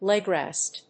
アクセントlég‐rèst